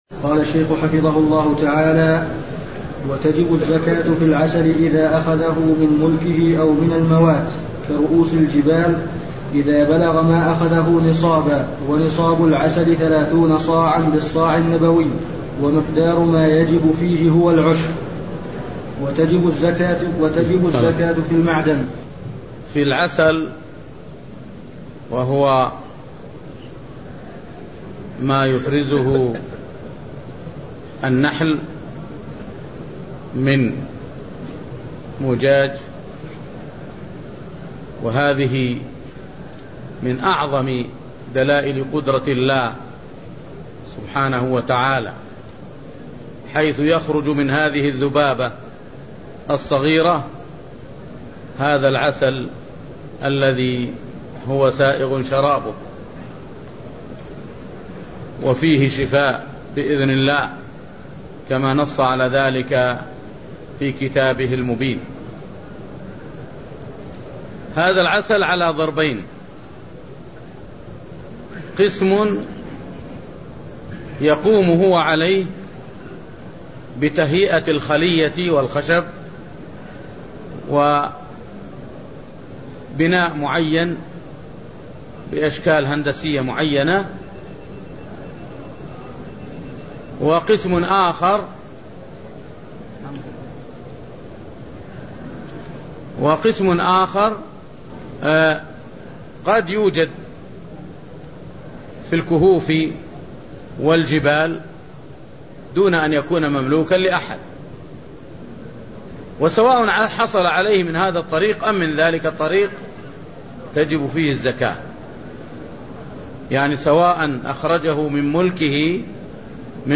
المكان: المسجد النبوي الشيخ: فضيلة الشيخ د. صالح بن سعد السحيمي فضيلة الشيخ د. صالح بن سعد السحيمي كتاب الزكاة (0004)